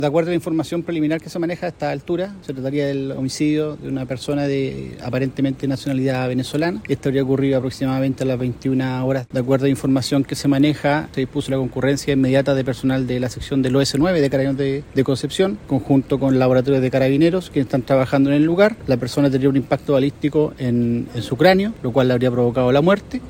Así las cosas, un certero disparo en la cabeza terminó con la vida del hombre, según lo detalló el Fiscal de Concepción, Matías Arellano.
cuna-fiscal.mp3